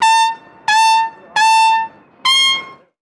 Toque de corneta 4
aerófono
corneta
llamada
metal
militar